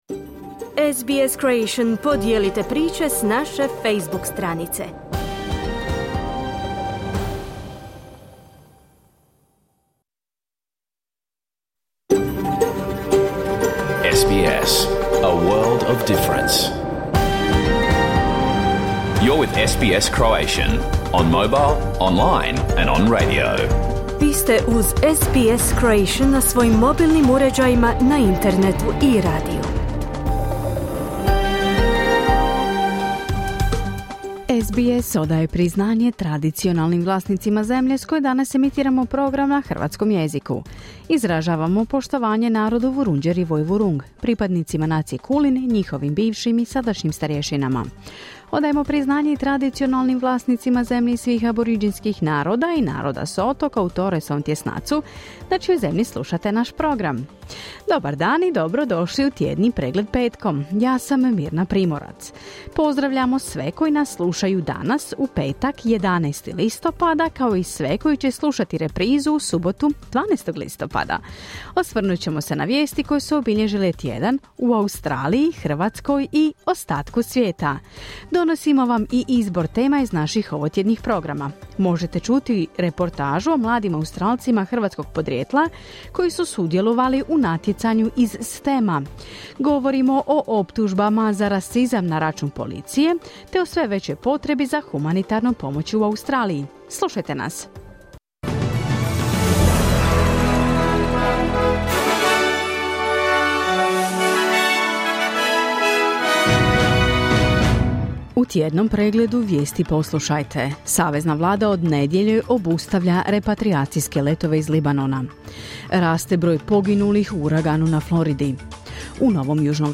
Vijesti i aktualne teme iz Australije, Hrvatske i ostatka svijeta. Program je emitiran uživo na radiju SBS1 u petak, 11. listopada, u 11 sati po istočnoaustralskom vremenu.